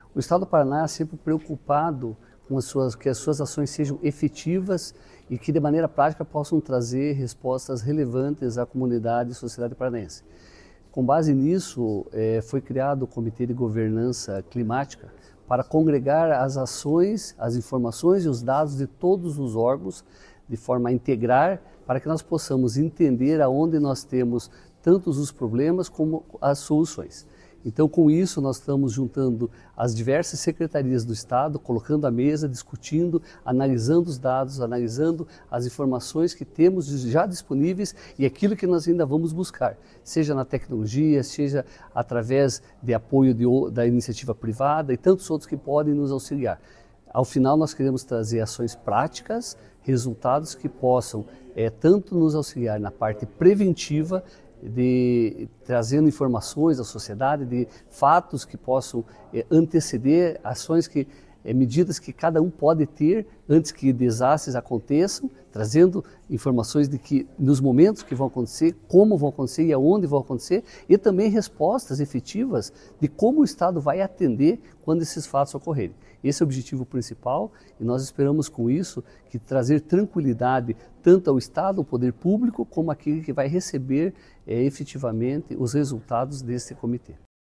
Sonora do chefe do Centro Estadual de Desburocratização da Casa Civil